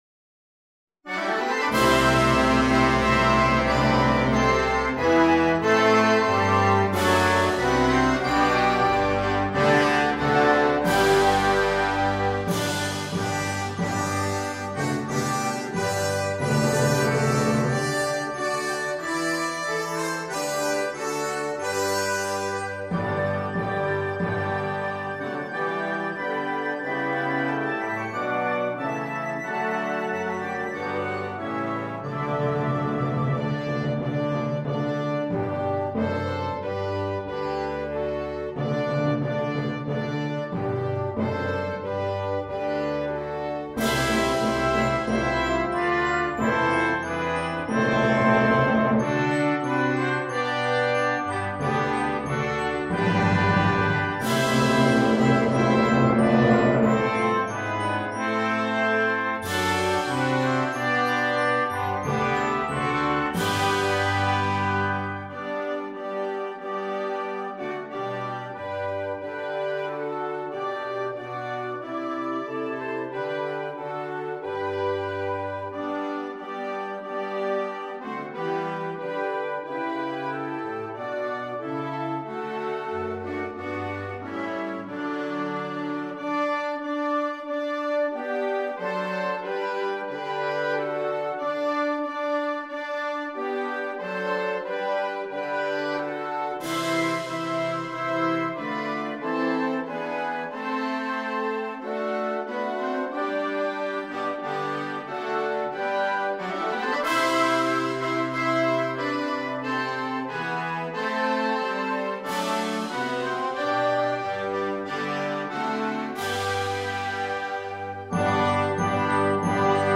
The Herald Angels Sing (Concert Band)
Hark-The-Herald-Angels-Sing-Concert-Band.mp3